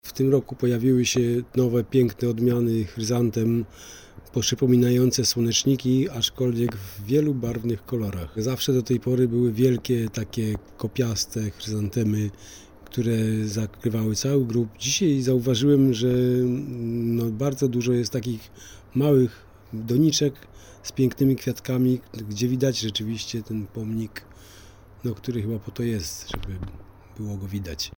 Zaglądamy na parafialny cmentarz św. Jacka na wrocławskich Swojczycach, pytając wrocławianki i wrocławian o to jak zmieniają się cmentarze.